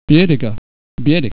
TABLE 3: Word End Voicing